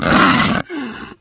flesh_pain_3.ogg